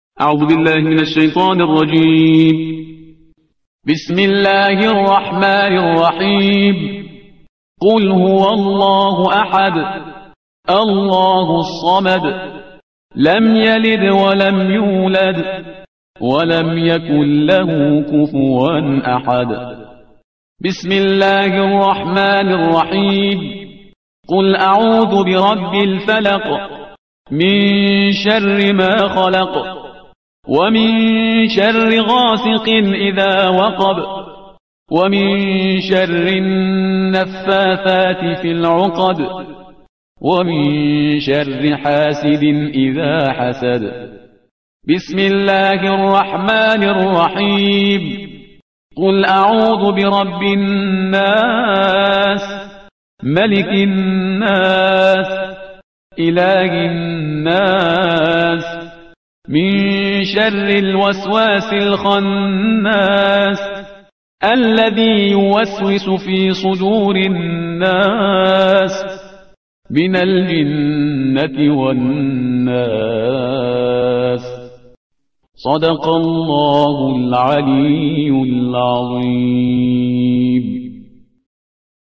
ترتیل صفحه 604 قرآن – جزء سی ام